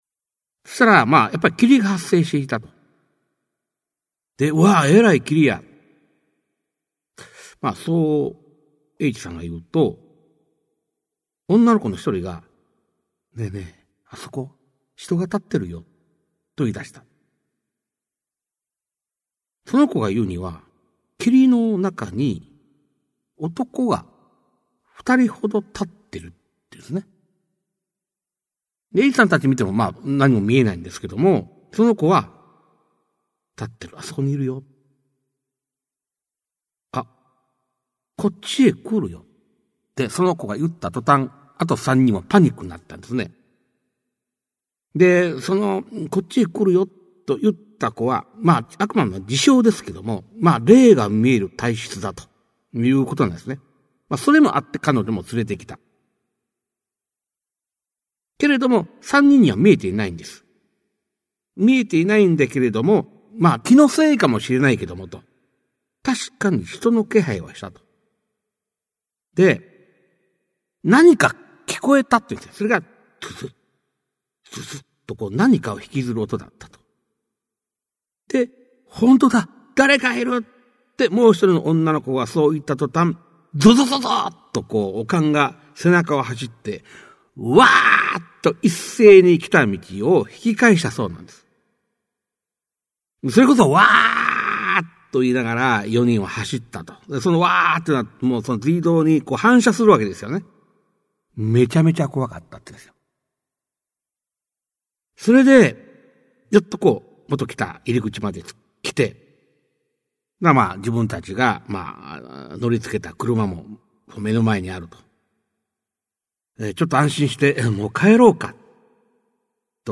[オーディオブック] 市朗怪全集 五十六
実話系怪談のパイオニア、『新耳袋』シリーズの著者の一人が、語りで送る怪談全集! 1990年代に巻き起こったJホラー・ブームを牽引した実話怪談界の大御所が、満を持して登場する!!